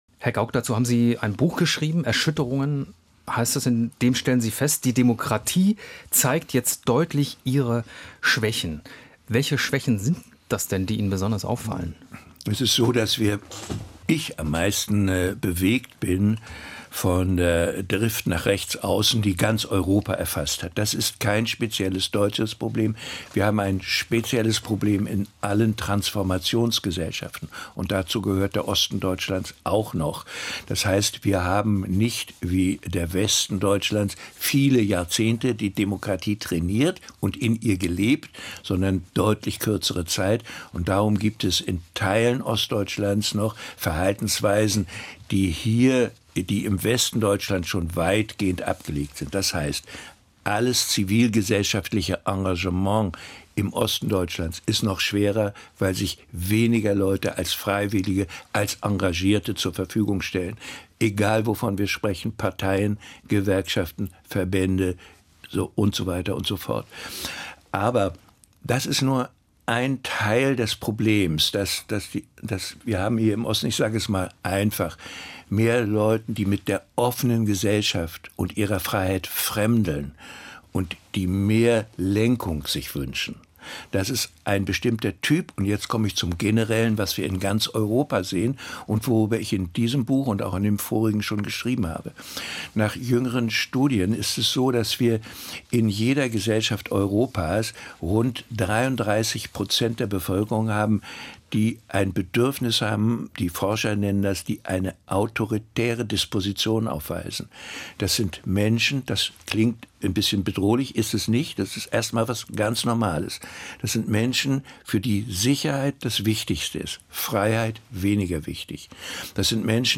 Das Gespräch wurde im August 2023 geführt.